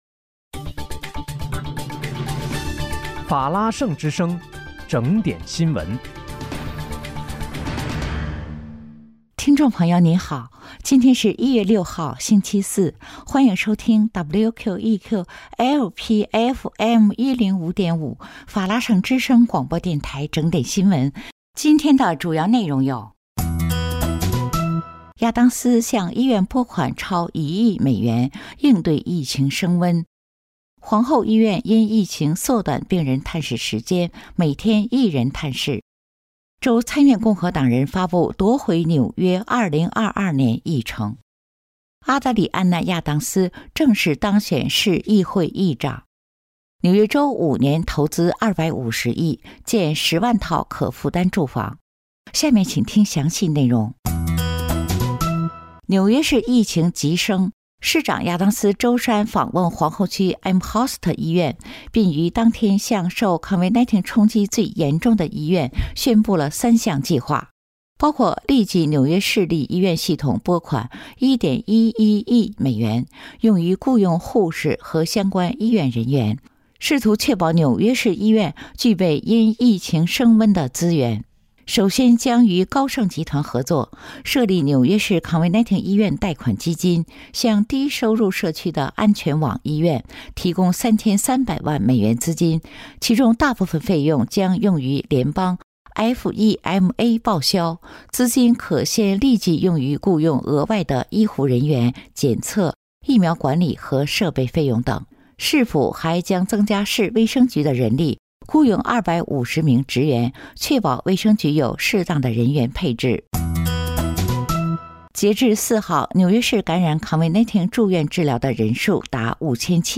1月6日（星期四）纽约整点新闻